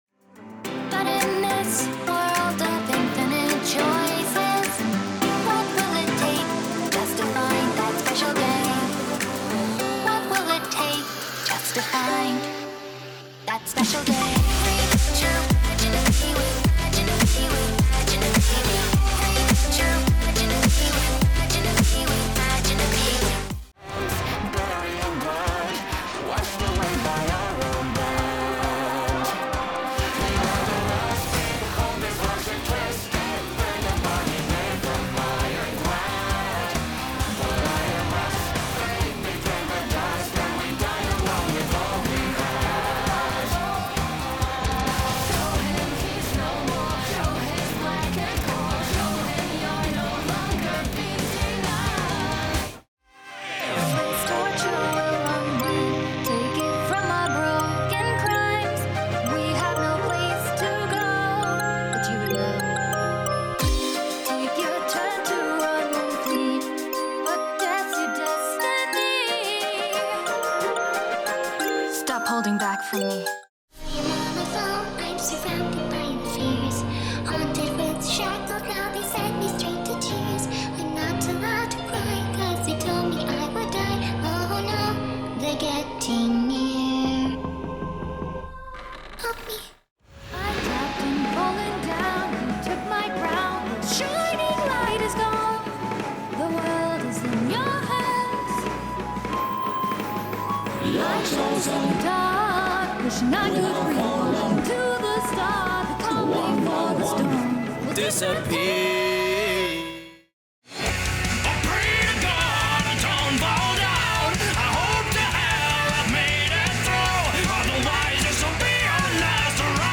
Singing Showreel
Their natural speaking voice, clear and mid-to-low pitched with a captivating transatlantic accent, has captivated global audiences.
Irish
Northern Irish
Bright
Upbeat
Youthful